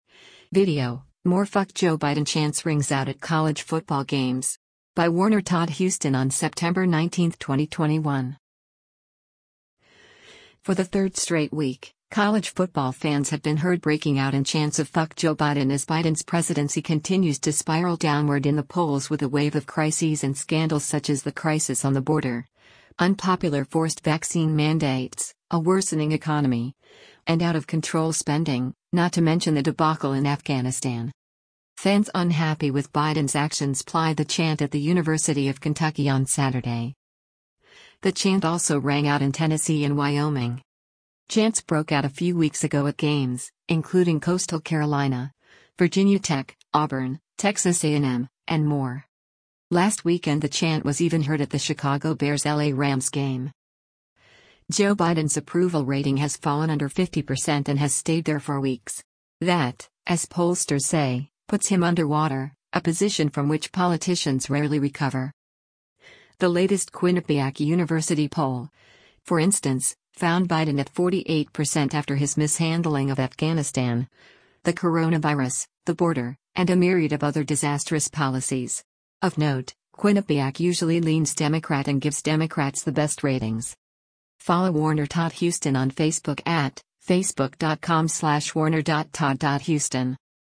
VIDEO: More ‘F*ck’ Joe Biden Chants Rings Out at College Football Games
For the third straight week, college football fans have been heard breaking out in chants of “F*ck Joe Biden” as Biden’s presidency continues to spiral downward in the polls with a wave of crises and scandals such as the crisis on the border, unpopular forced vaccine mandates, a worsening economy, and out of control spending, not to mention the debacle in Afghanistan.
Fans unhappy with Biden’s actions plied the chant at the University of Kentucky on Saturday: